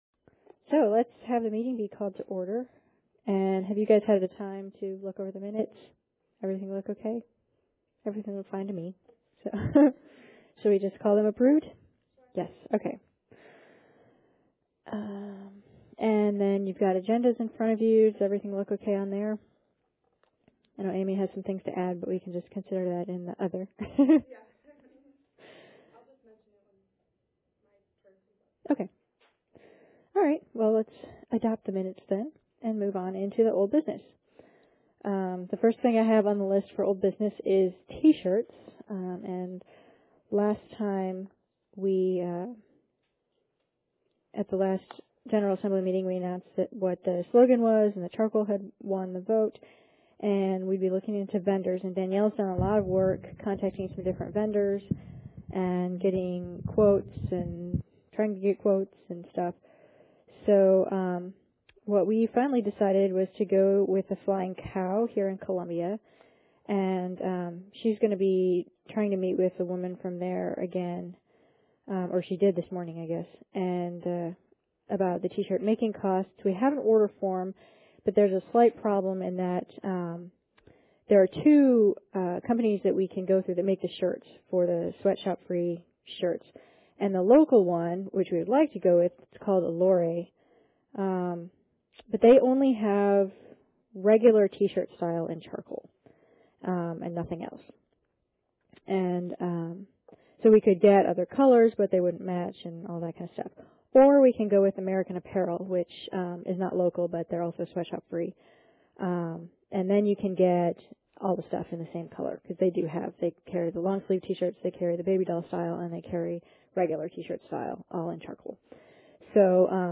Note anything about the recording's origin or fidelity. Students in the Library Information School Graduate Student Association hold their monthly meeting & webcast it for the benefit of students who live in other areas or who have to work.